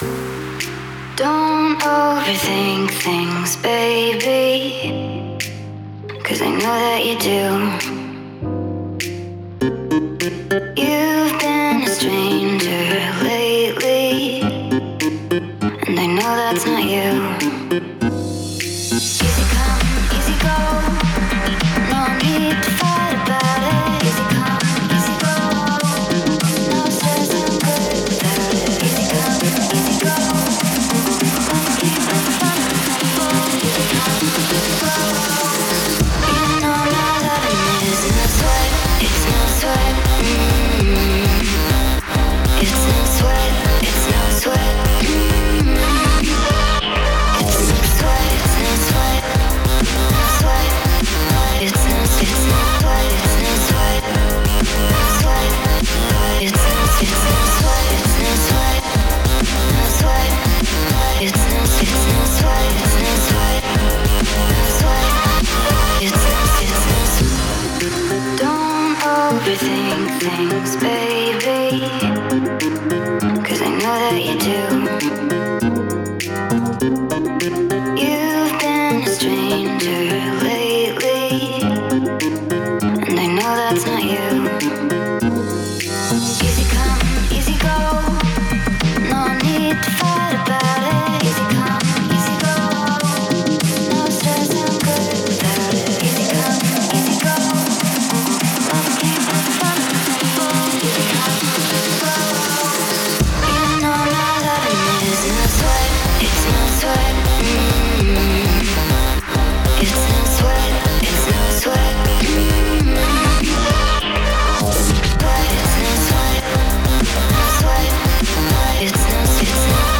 это энергичная трек в жанре поп-музыки